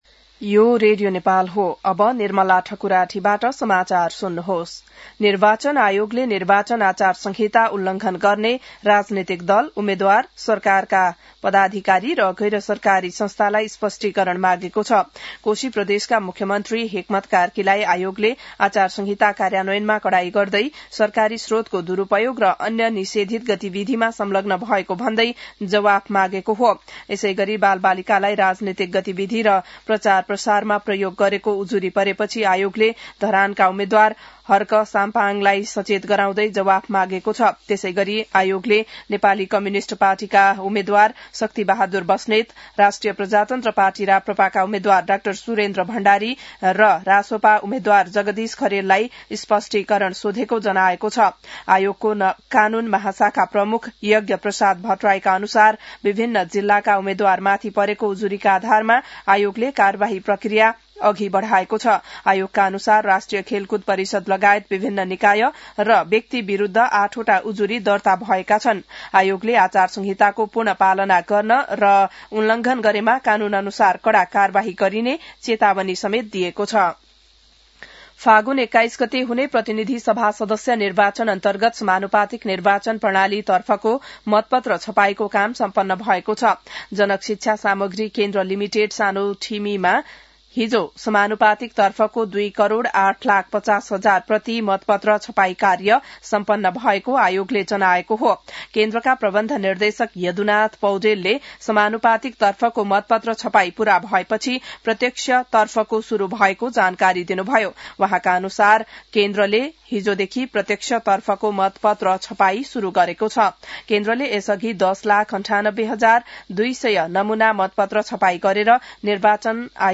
बिहान १० बजेको नेपाली समाचार : १३ माघ , २०८२